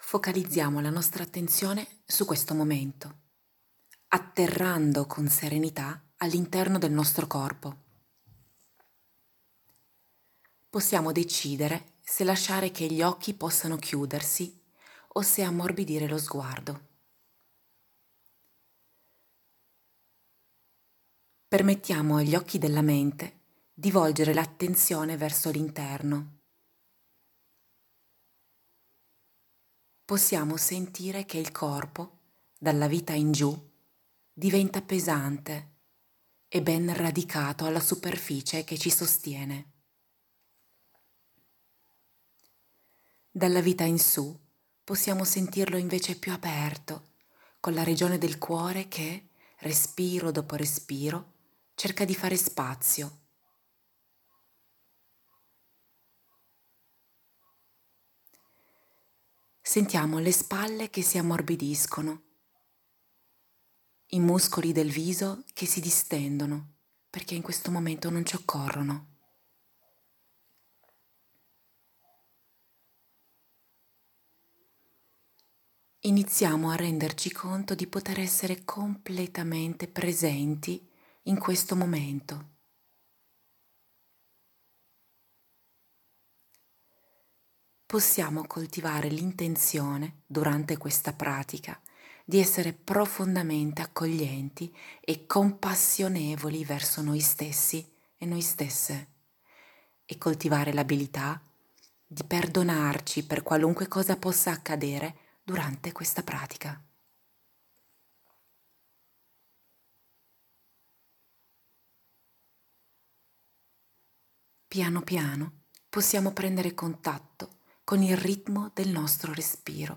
Pratica compassionevole
Istruzioni per la meditazione sul respiro
Seguendo le indicazione della mia voce, proviamo semplicemente a coordianare e a bilanciare l'attività simpatica con quella parasimpatica, cercando di aumentare armoniosamente e lentamente il tempo tra un respiro e l'altro.
Nota bene: Per semplicità, nell'audio, suggerisco di contare, nella mente, per accompagnare il respiro e regolarlo (1, 2, 3, pausa) ma, a volte, il conteggio può risultare fastidioso; se così fosse, proviamo allora a tenere il tempo immaginando, sempre con gli occhi chiusi, per esempio, la figura di un triangolo o di un quadrato, e immaginiamo di scorrere con lo sguardo su ciascun lato a ogni tempo.